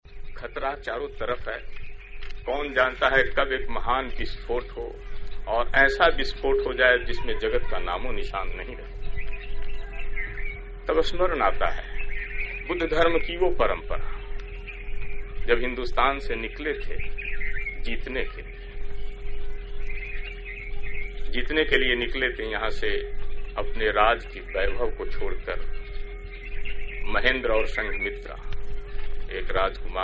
Audio speeches of Babu Jagjivan Ram outside Parliament
Buddha Jayanti- Jagjivan Ram (Speaker) 5-5-63